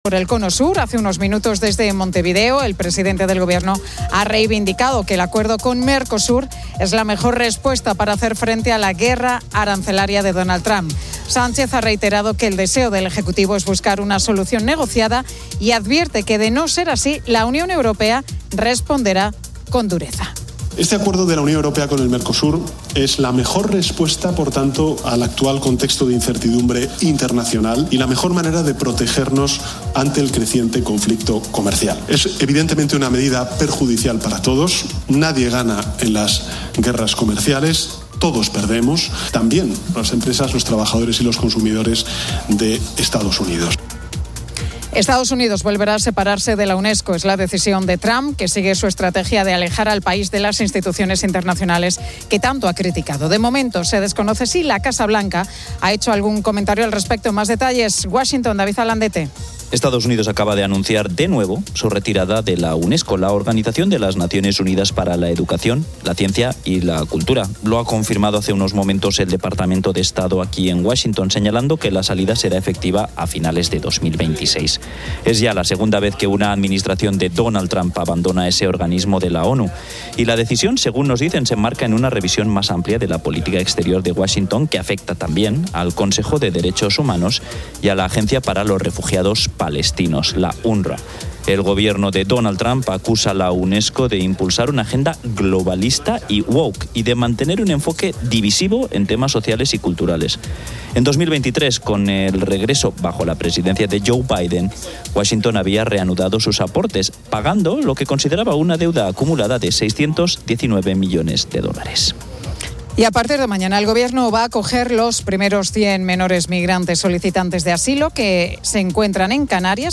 La Tarde 18:00H | 22 JUL 2025 | La Tarde Pilar García Muñiz se traslada hasta el parque de El Retiro para hablar acerca de si es necesario cerrar los espacios verdes cuando hay excesivas temperaturas.